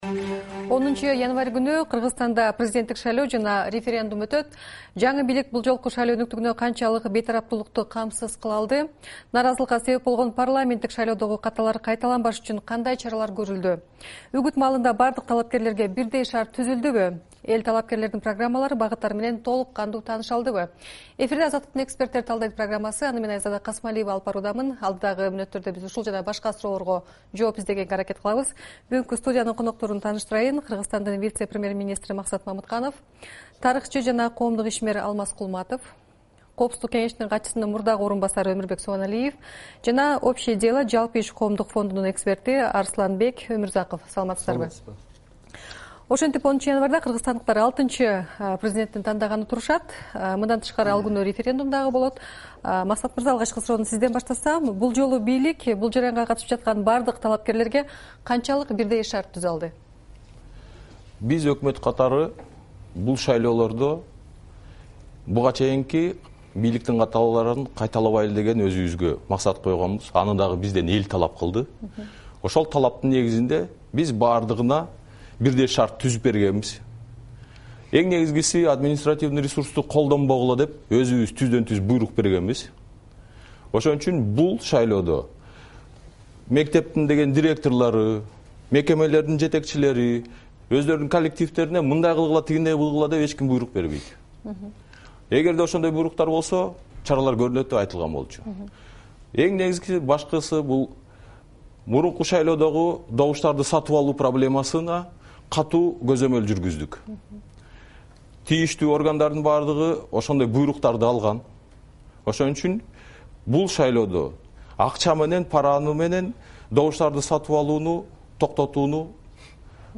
Студиянын коноктору